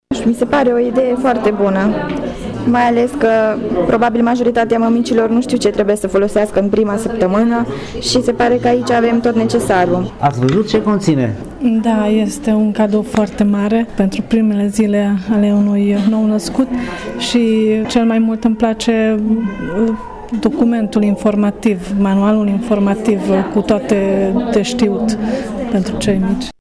Astăzi, la Secția de Neonatologie a Spitalului Clinic Județean de Urgență Tîrgu-Mureș, Kaufland România și Organizația ”Salvați Copiii” au oferit viitoarelor mămici 100 de cutii cu produse de igienă și îmbrăcăminte de primă necesitate.
Viitoarele mămici s-au bucurat mult de cadouri: